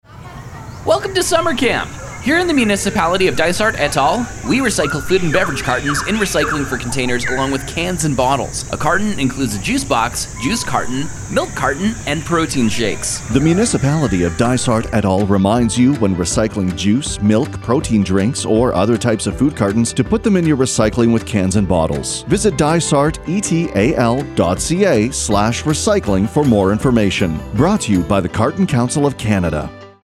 Dysart-et-al-Radio-Ad-2-Summer-Camp.mp3